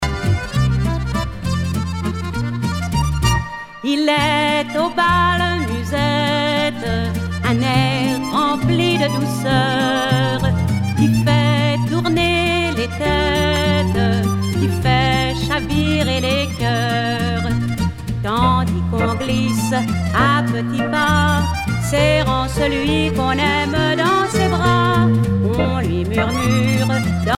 valse musette
Pièce musicale éditée